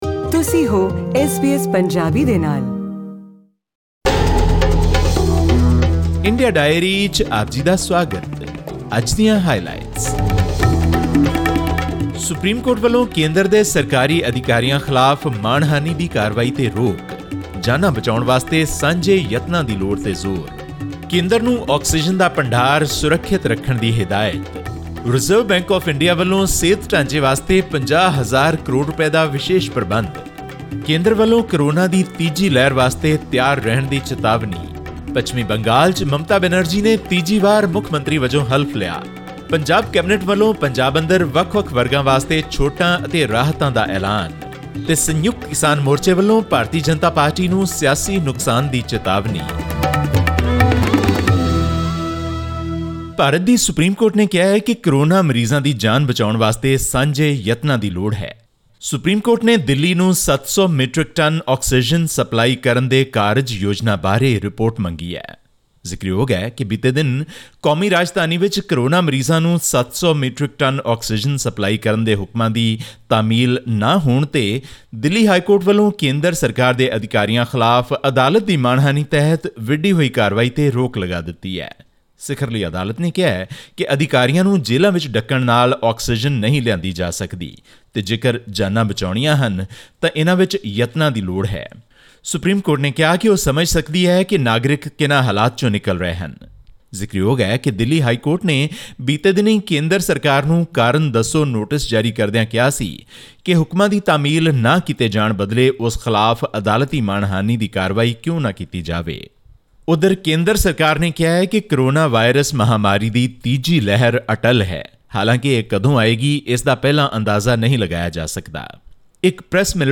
The Reserve Bank of India (RBI) has unveiled a series of liquidity measures to help banks support the healthcare facilities and small borrowers severely impacted by the deadly second wave of COVID-19. All this and more in our weekly news segment from India.